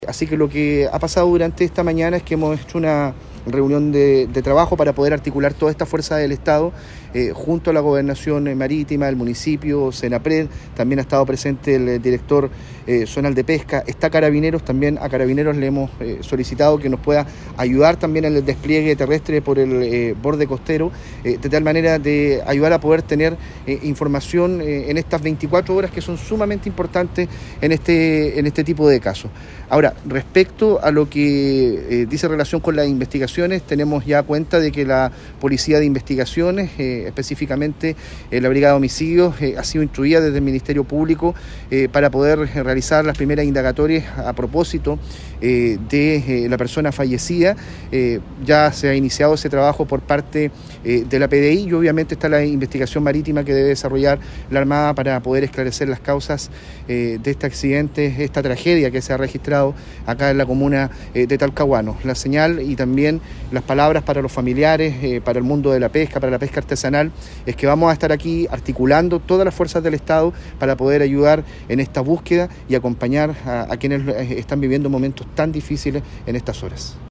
Por su parte, el delegado presidencial del Biobío, Eduardo Pacheco, se refirió a las coordinaciones de los organismos del Estado para apoyar en la búsqueda terrestre.